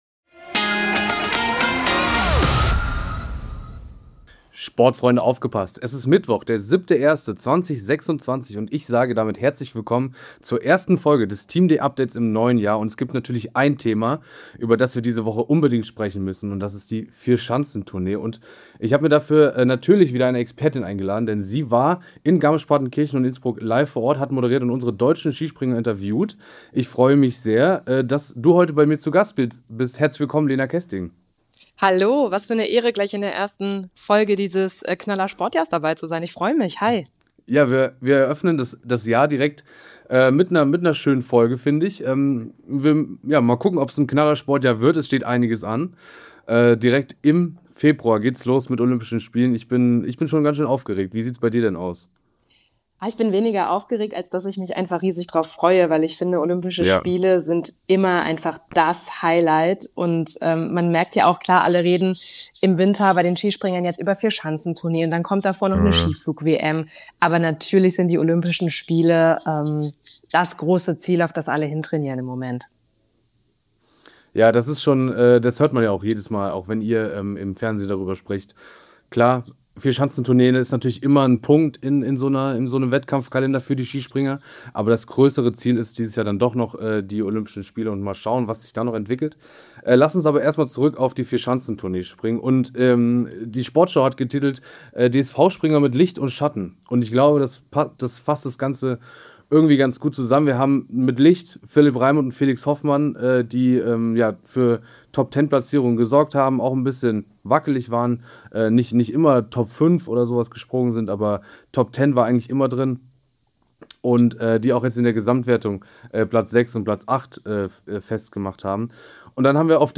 Es gibt natürlich ein Thema über das wir diese Woche unbedingt sprechen müssen und das ist die Vierschanzentournee. Und dafür habe ich mir natürlich wieder eine Expertin eingeladen: Sie war in...